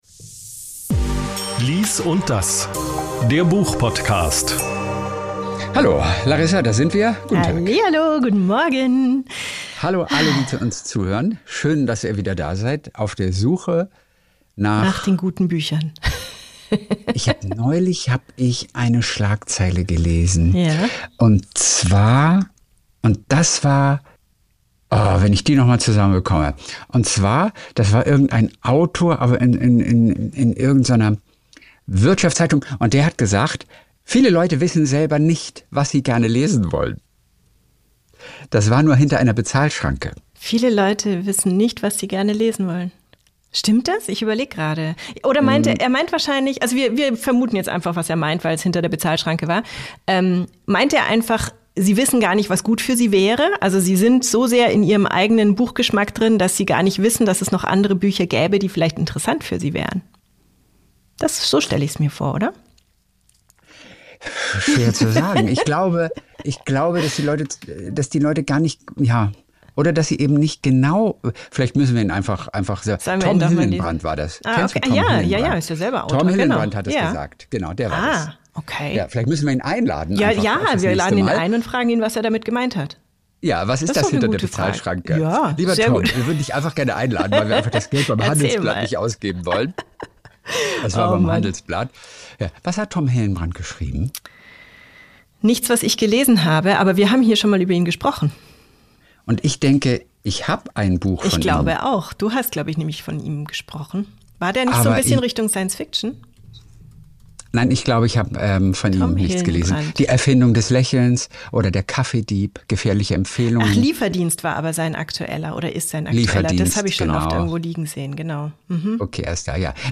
Die Folge mit Bernhard Hoëcker als Gast und diesen Büchern: Dreifach | Und dahinter das Meer | Stay Away From Gretchen